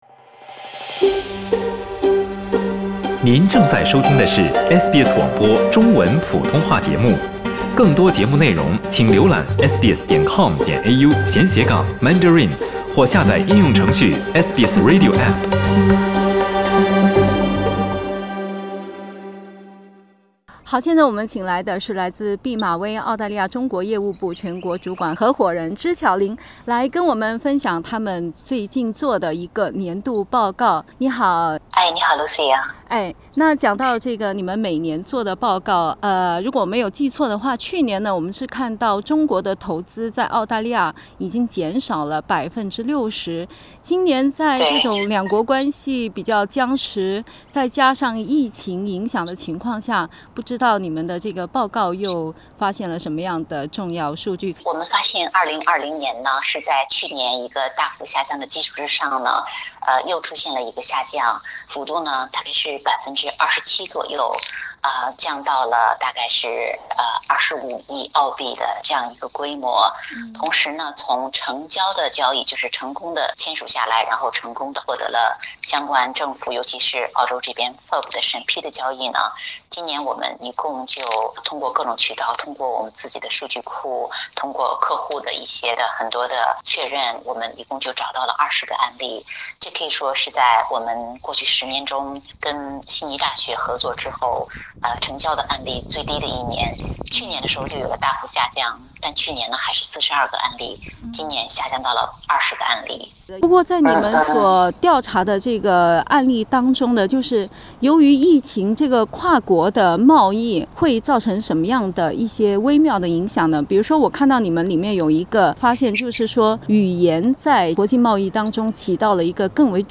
（本節目為嘉賓觀點，不代表本台立場，請聽寀訪） 澳大利亞人必鬚與他人保持至少1.5米的社交距離，請查看您所在州或領地的最新社交限制措施。